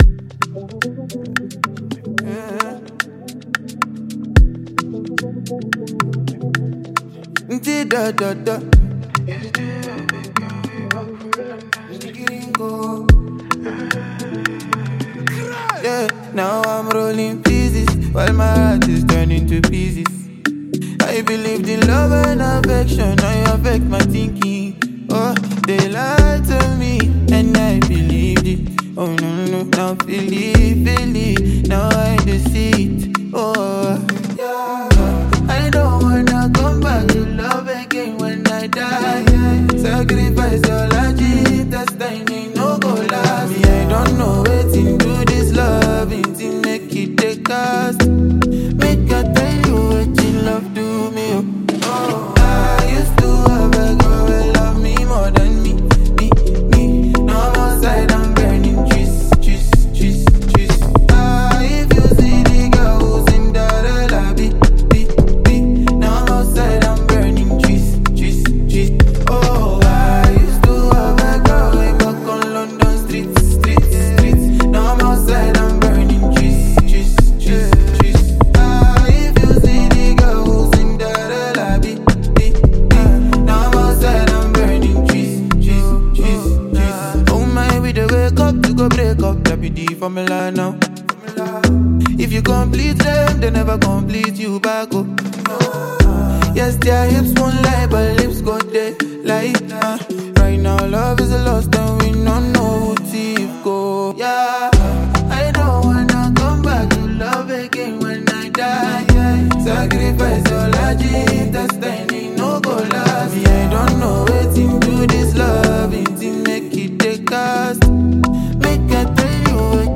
Multi-talented Ghanaian singer and songwriter